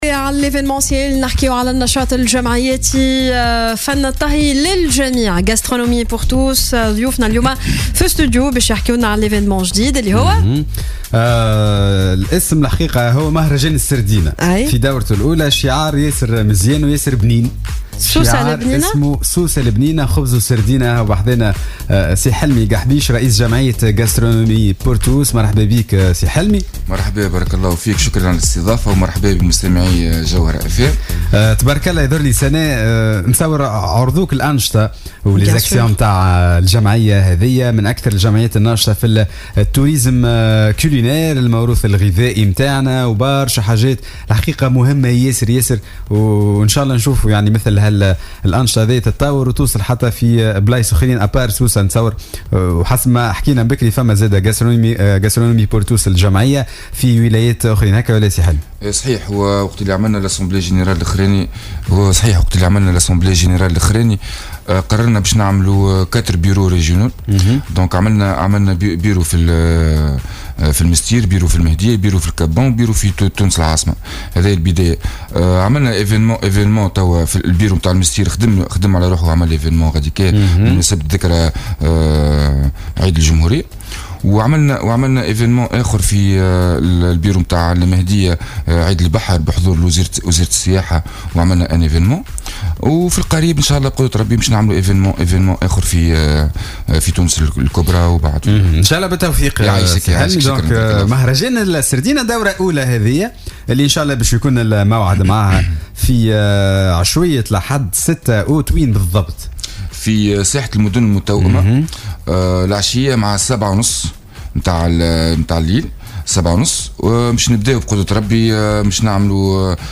ضيف صباح الورد على "الجوهرة أف أم"